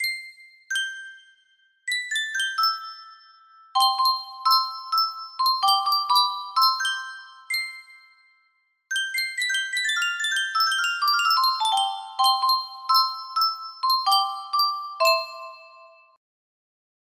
Yunsheng Music Box - Day-O The Banana Boat Song 5960 music box melody
Full range 60